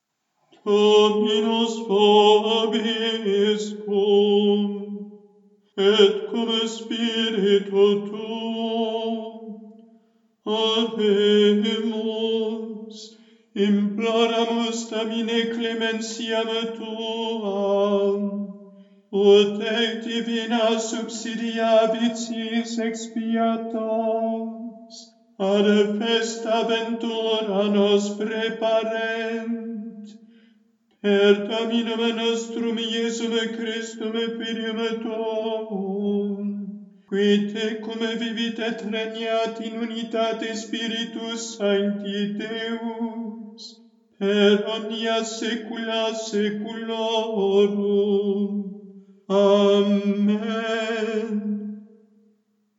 Postcommunion